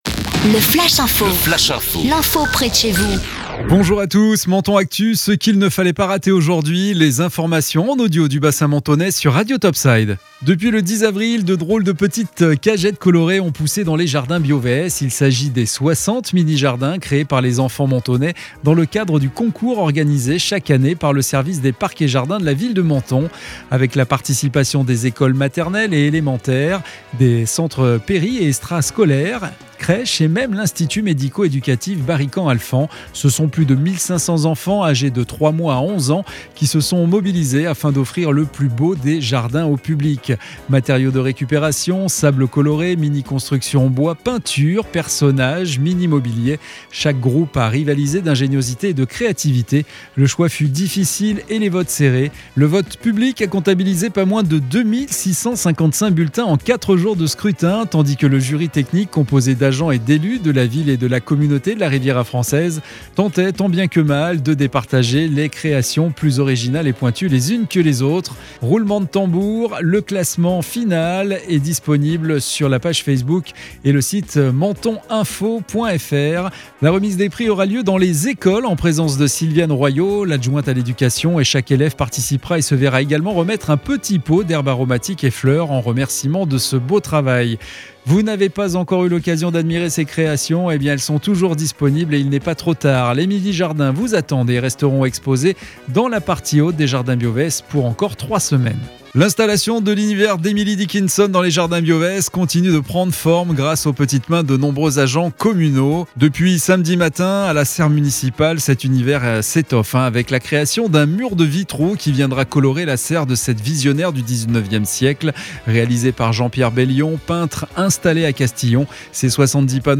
Menton Actu - Le flash info du dimanche 18 avril 2021